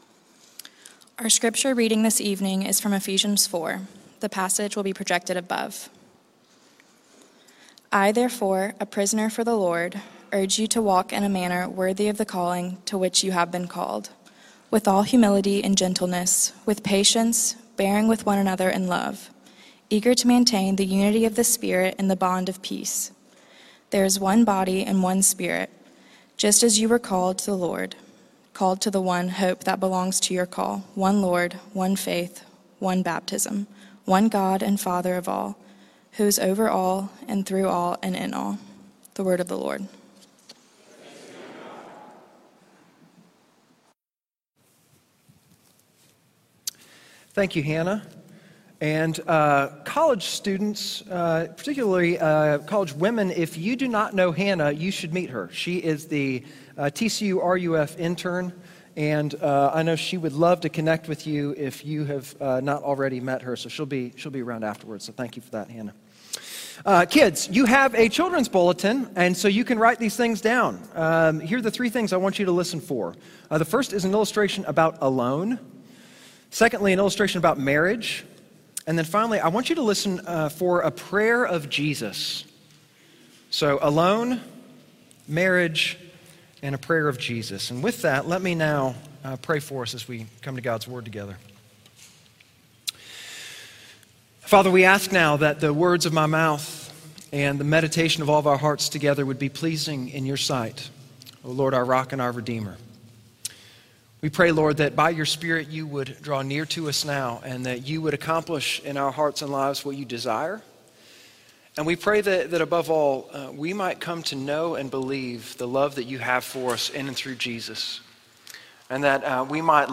Sermons Home Sermons